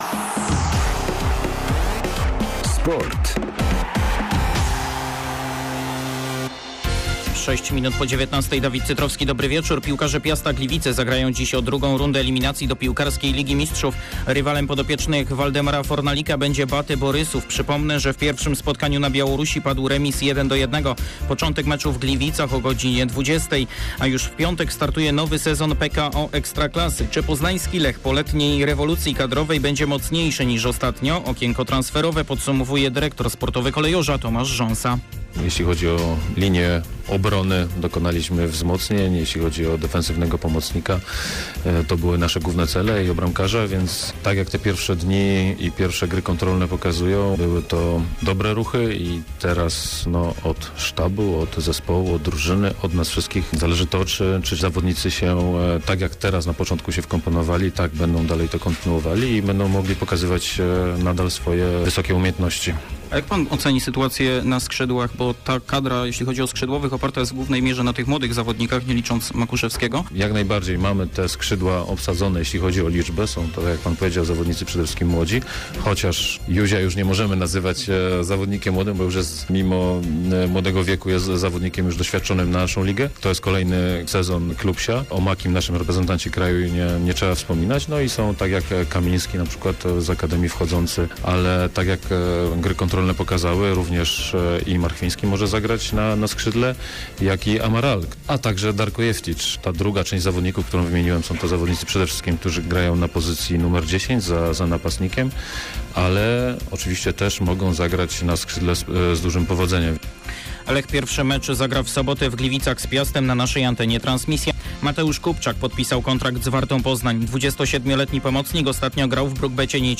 17.07. serwis sportowy godz. 19:05